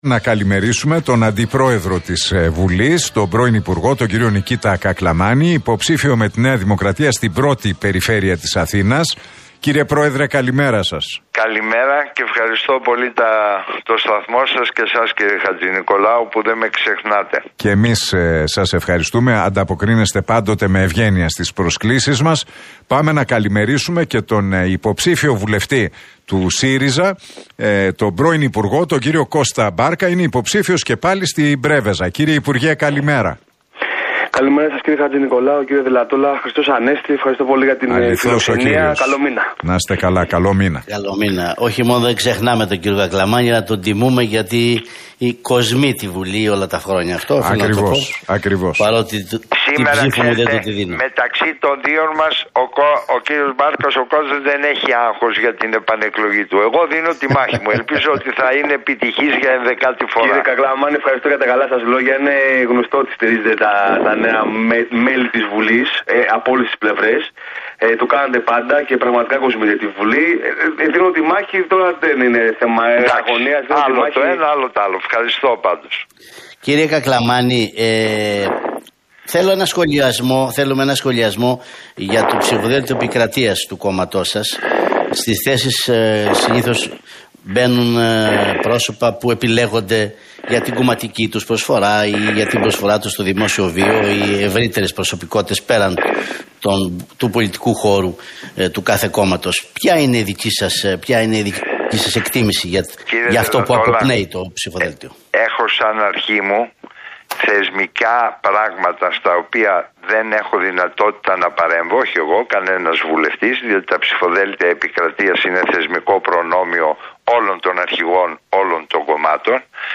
Debate Κακλαμάνη - Μπάρκα στον Realfm 97,8: Οι εκλογές και το «μπλόκο» στο κόμμα Κασιδιάρη